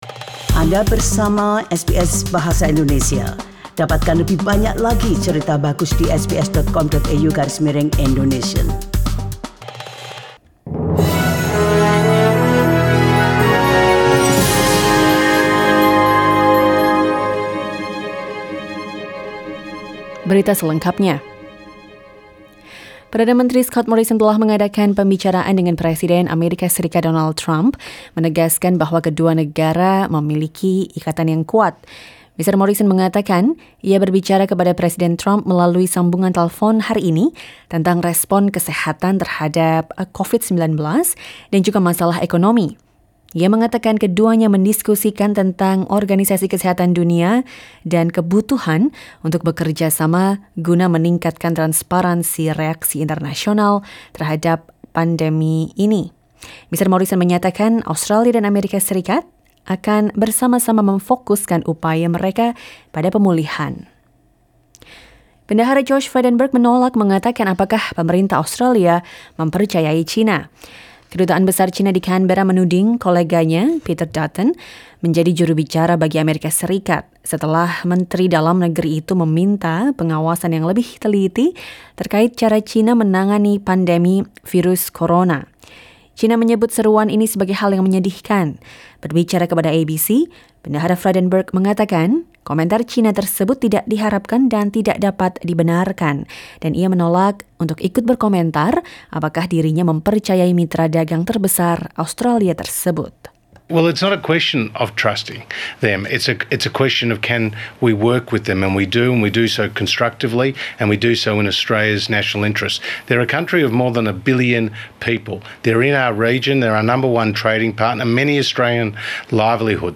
SBS Radio news in Indonesian - 22 April 2020